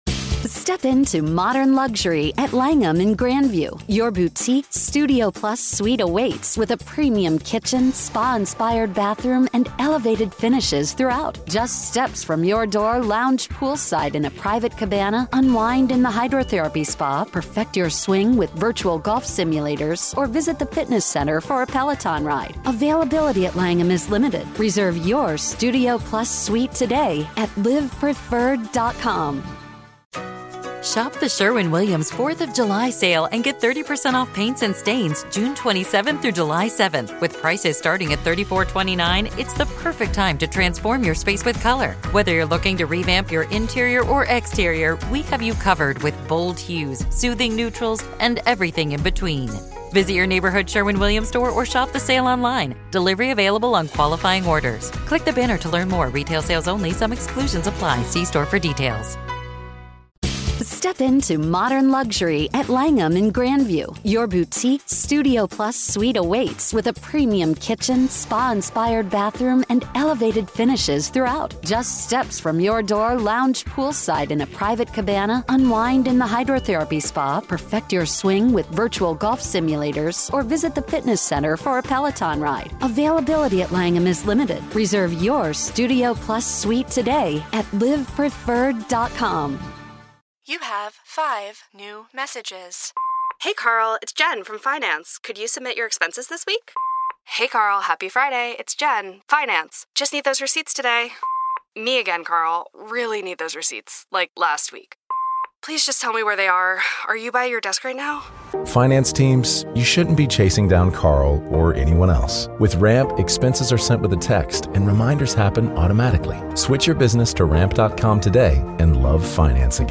Experience the raw courtroom drama firsthand as we delve into the "Rust" movie shooting trial with unfiltered audio and expert analysis.
Go beyond the headlines: Hear the emotional pleas and heated arguments directly from the courtroom. Gain insider insights from legal experts as they break down the complex charges, the defense strategy, and the potential impact on the industry.